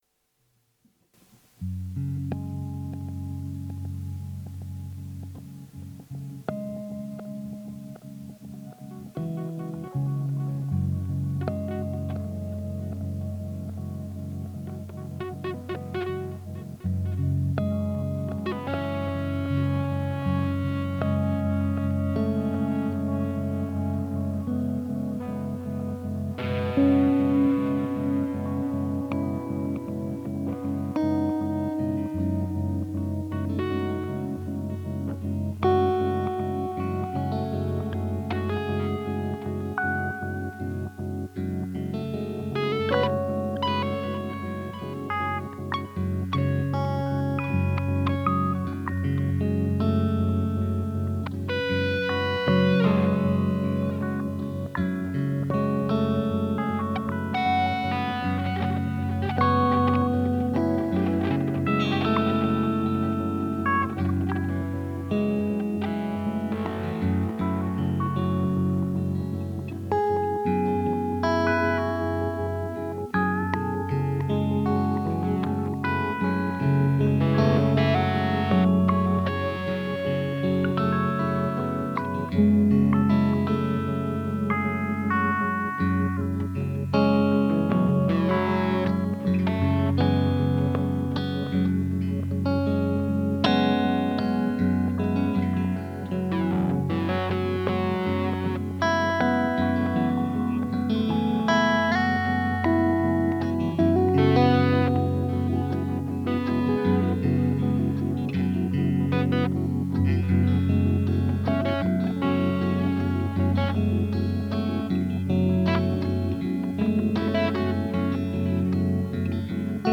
Wieder zuhause, fing es an zu regnen, und ich fand den Mut, mein musikalisches Equipment anzuschliessen.
Sehr schöne reine Klänge.